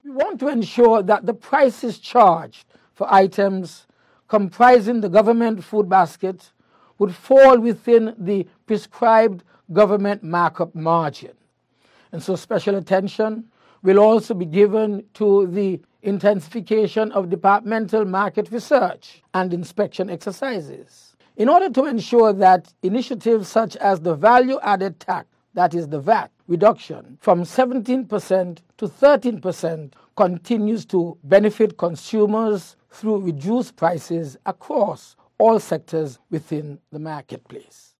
This is Minister of Commerce and Consumer Affairs, Dr. Denzil Douglas: